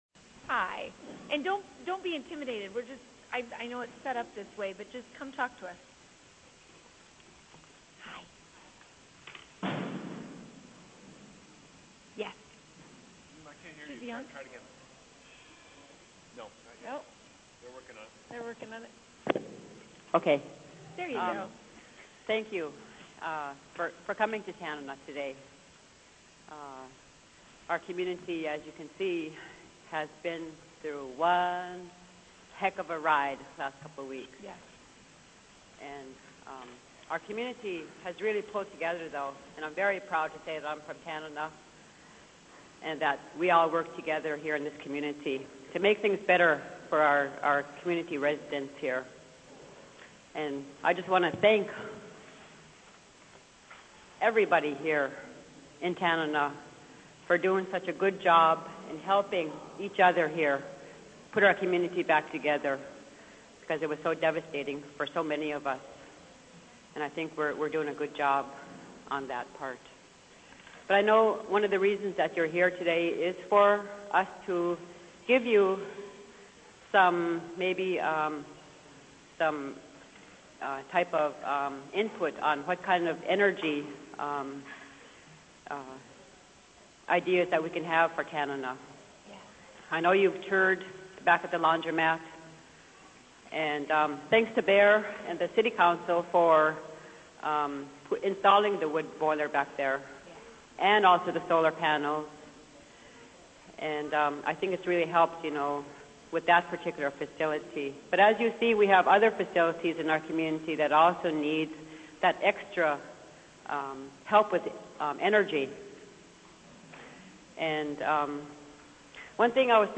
Public Hearing on Statewide Energy Plan
Location: Tanana Community Center